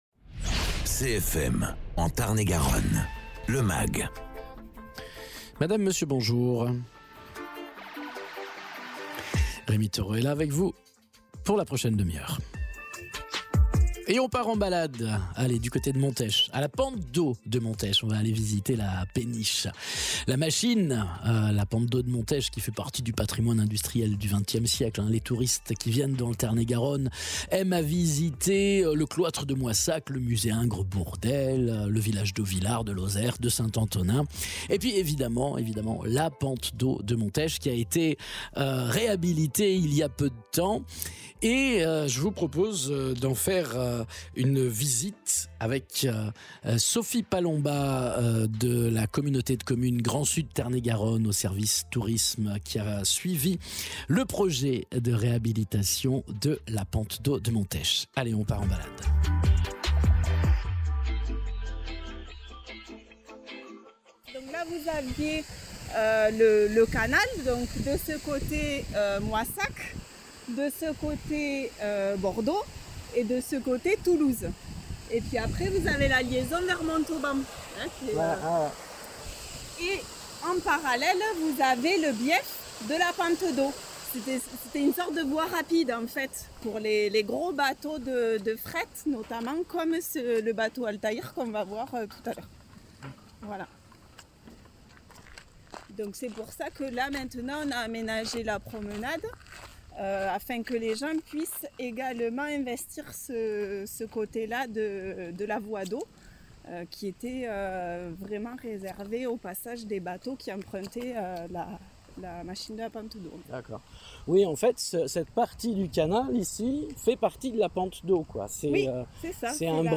Visite guidée de la pente d’eau de Montech